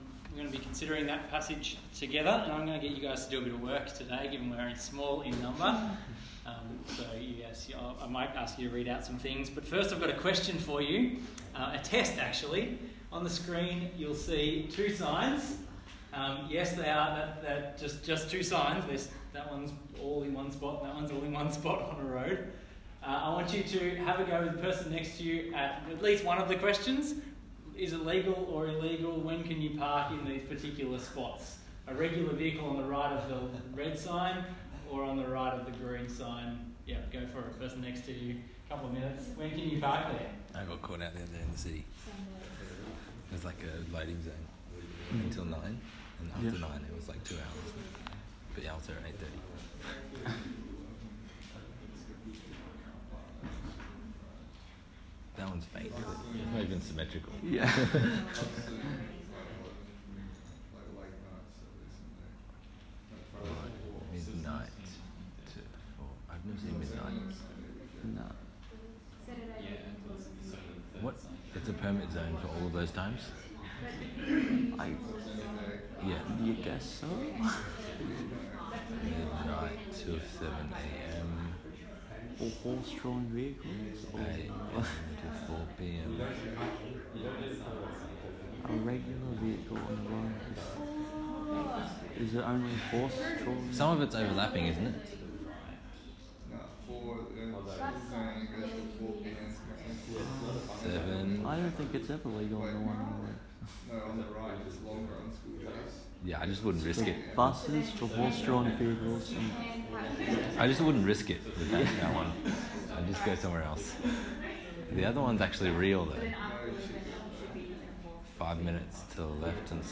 Bible Talk